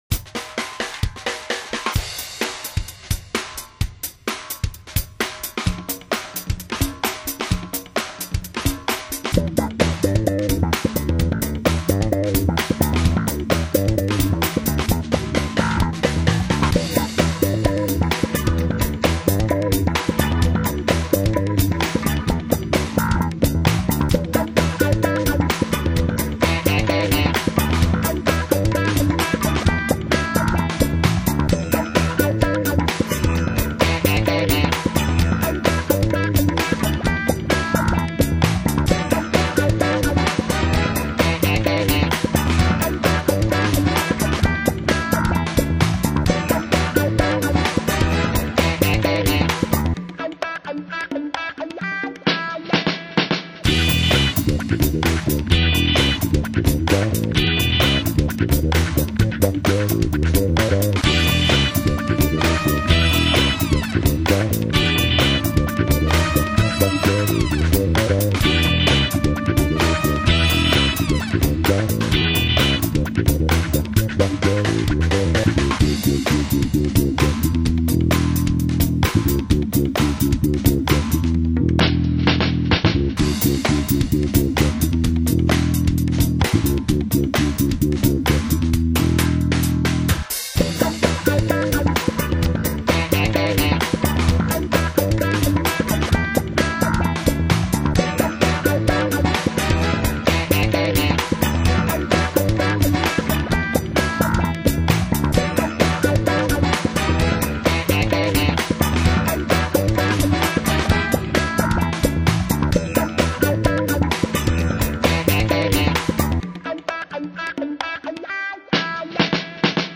play his Smith 4-String!